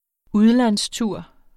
Udtale [ ˈuðlans- ]